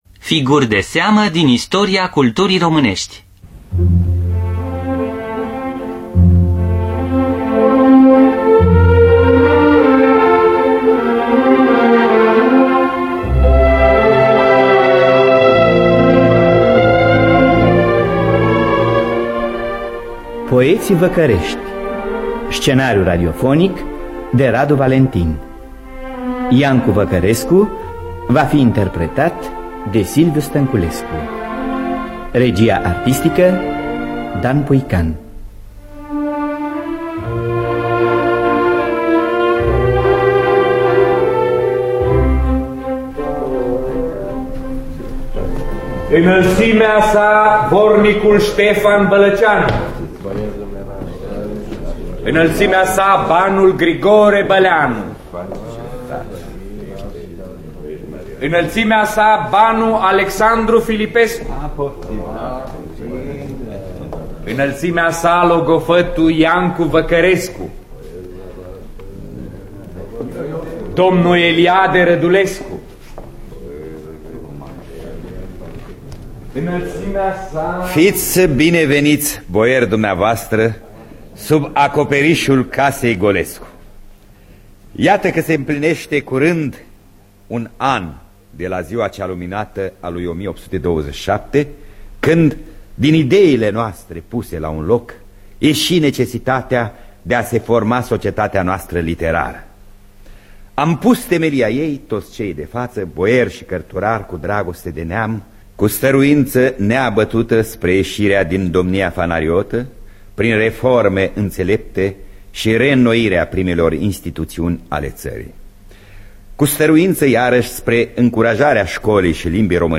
Biografii, memorii: Poeții Văcărești. Scenariu radiofonic de Radu Valentin.